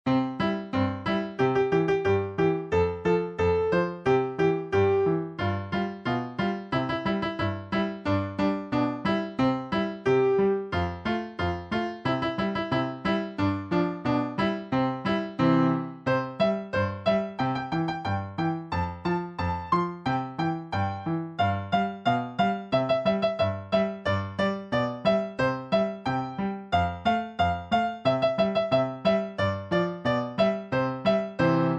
Traditional French Song Lyrics and Sound Clip